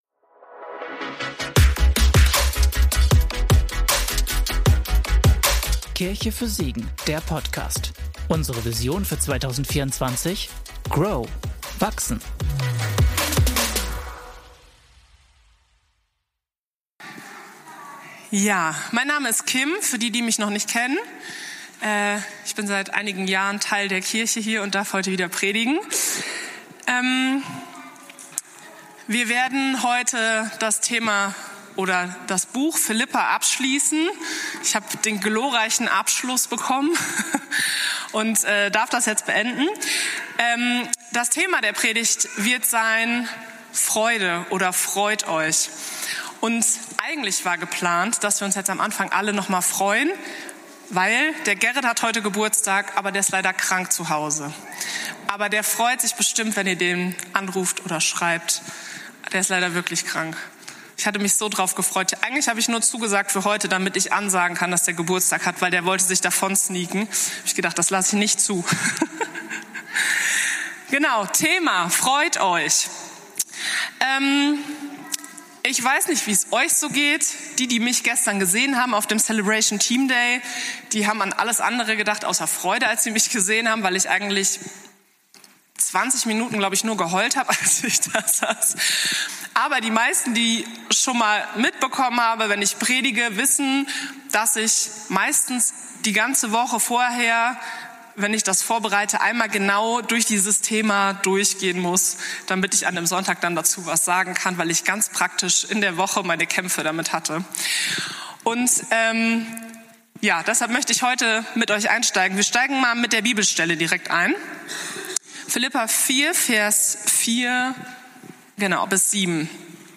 Philipperbrief - Predigtpodcast